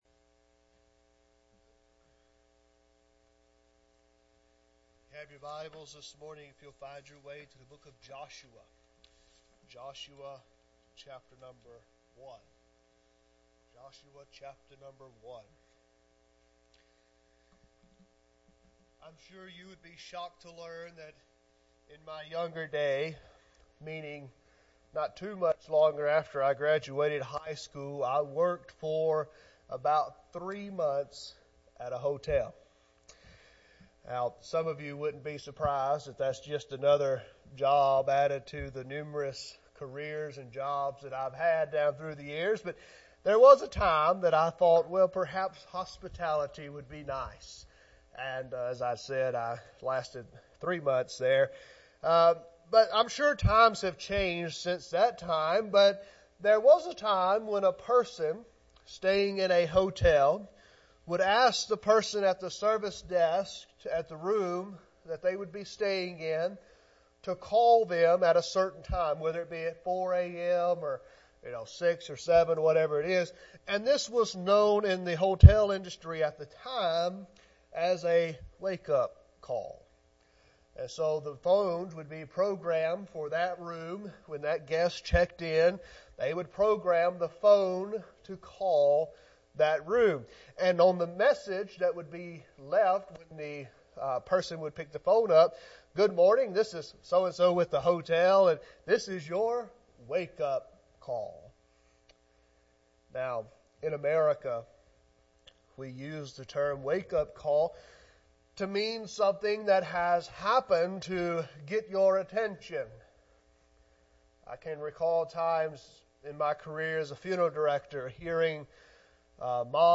Sermons | West Acres Baptist Church